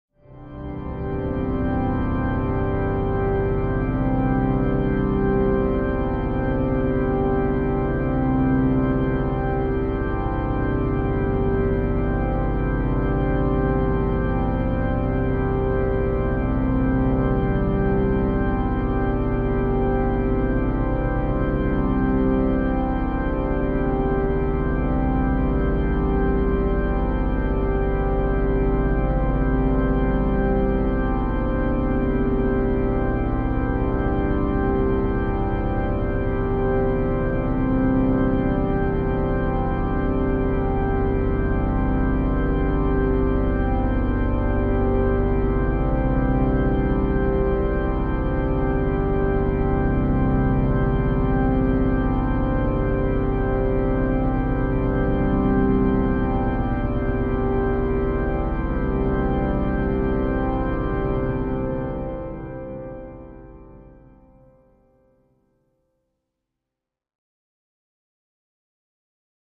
Music: Eerie Sustained Pipe Organ Chord.